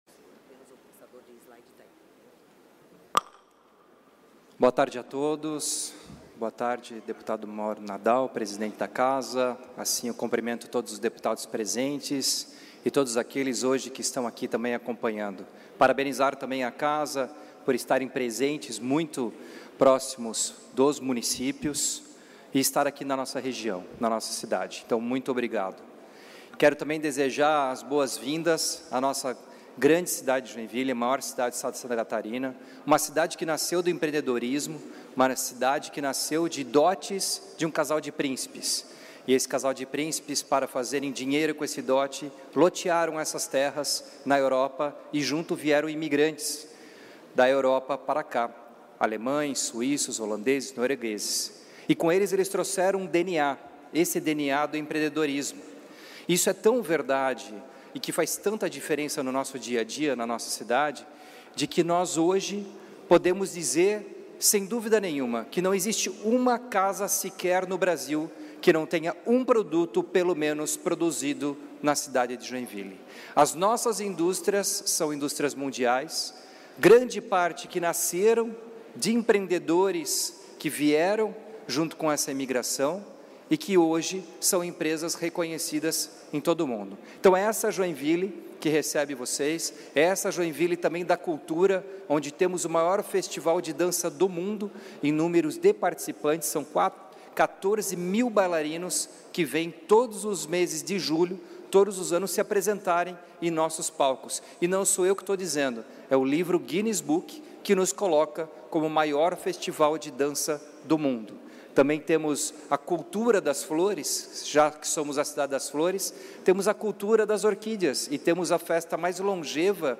Pronunciamentos das entidades da região Norte na sessão ordinária desta terça-feira (4)
- Adriano Silva - Prefeito de Joinville);